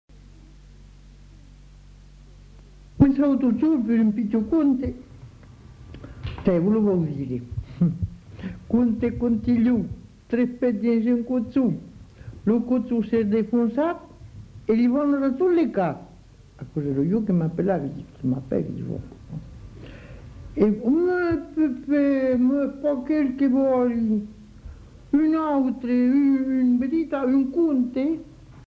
Aire culturelle : Haut-Agenais
Genre : conte-légende-récit
Effectif : 1
Type de voix : voix de femme
Production du son : parlé
Classification : contes-attrape